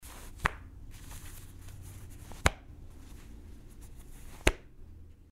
Предлагаем вам использовать звуки с шуршанием во время надевания и снятия одежды, такой как штаны, куртки для монтажа видео бесплатно.
2. Звук застёгивания кнопок одежды
zvuk-zasteg-knopok.mp3